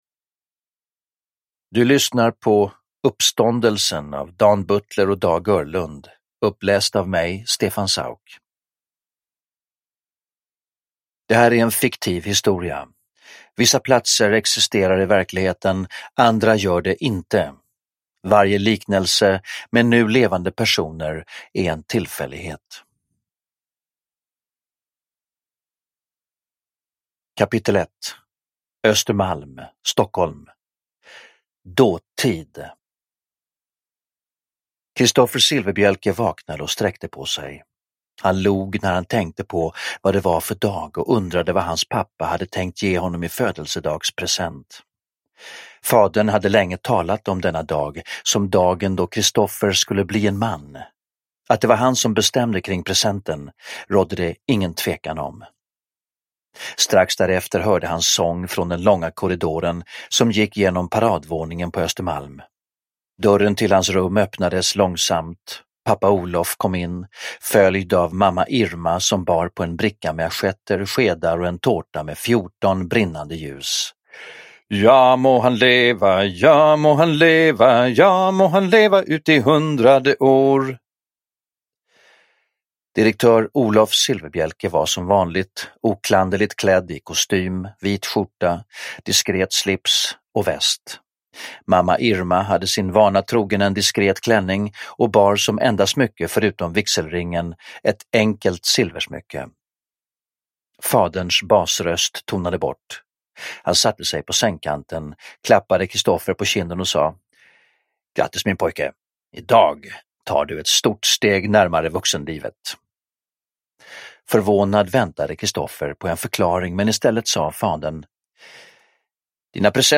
Silfverbielke 8 Uppståndelsen / Ljudbok